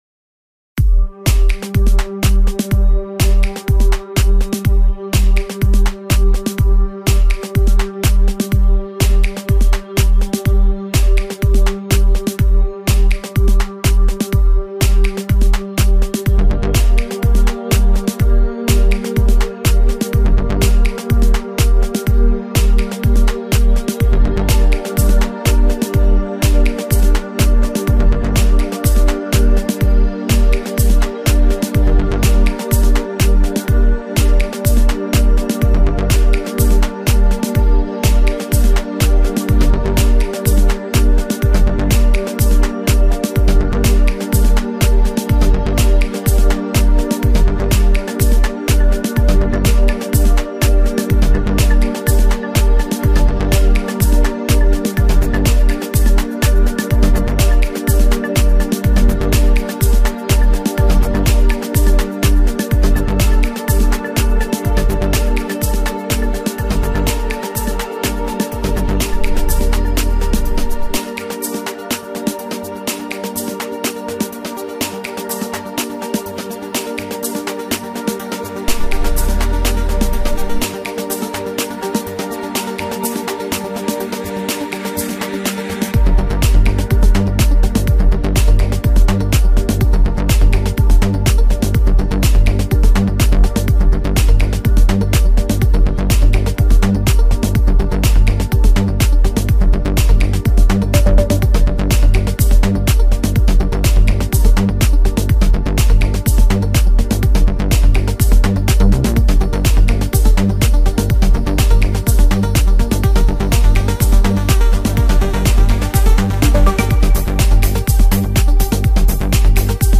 música electrónica
Su estilo puede definirse como Deep-Techno-Progressive House